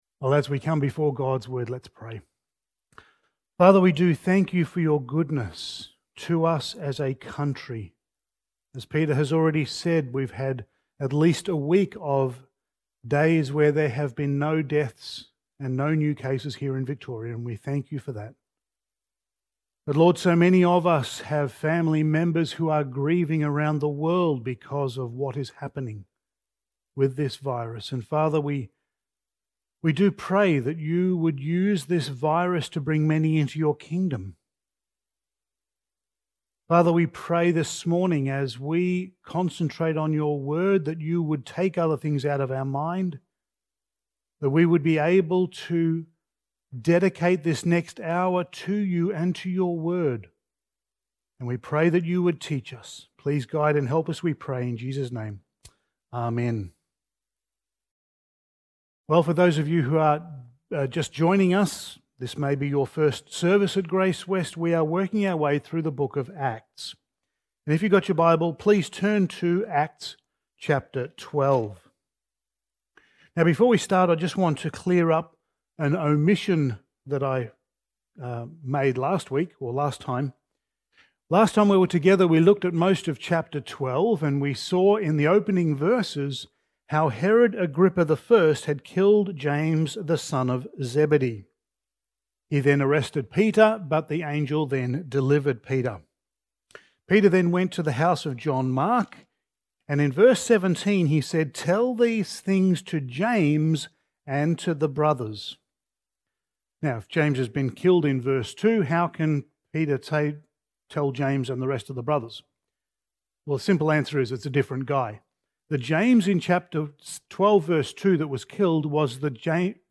Passage: Acts 12:25-13:12 Service Type: Sunday Morning